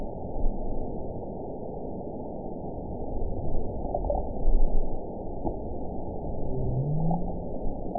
event 917169 date 03/22/23 time 23:34:22 GMT (2 years, 1 month ago) score 9.66 location TSS-AB03 detected by nrw target species NRW annotations +NRW Spectrogram: Frequency (kHz) vs. Time (s) audio not available .wav